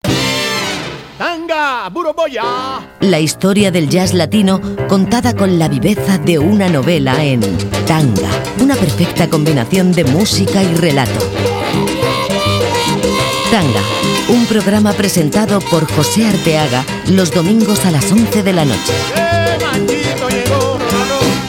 Careta del programa.
Musical